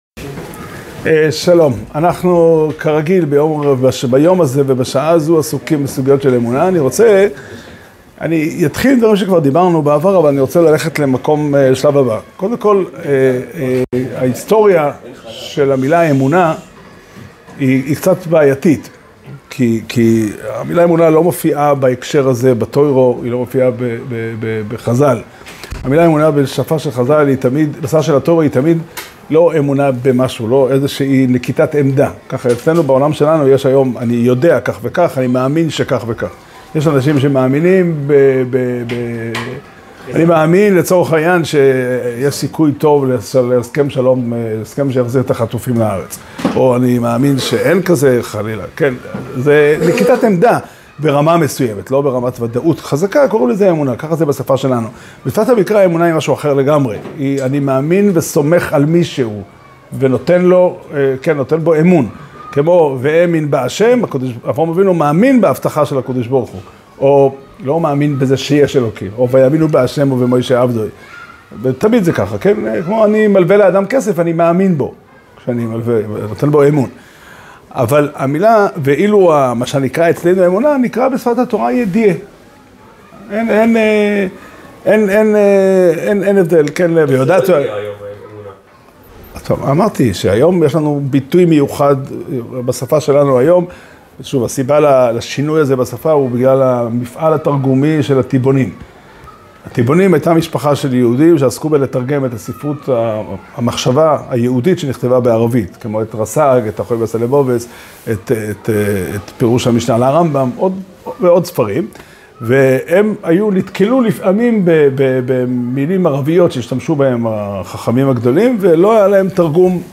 שיעור שנמסר בבית המדרש פתחי עולם בתאריך כ"ד כסלו תשפ"ה